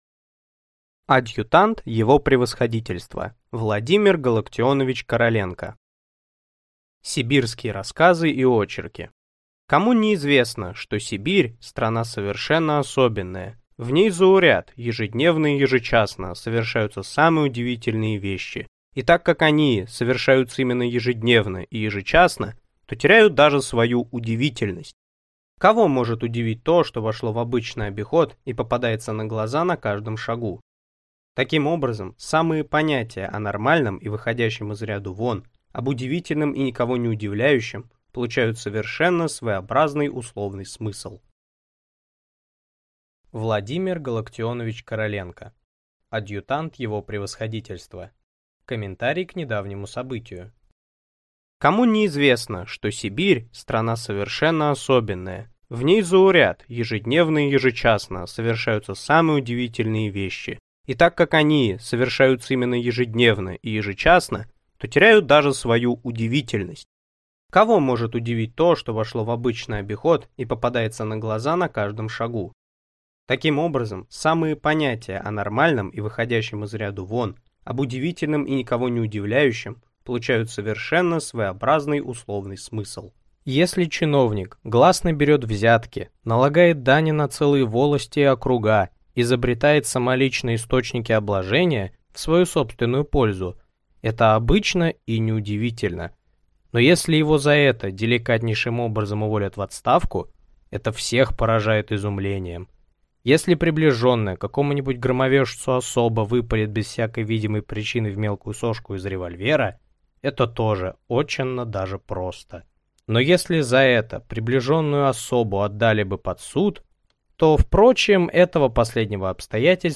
Аудиокнига Адъютант его превосходительства | Библиотека аудиокниг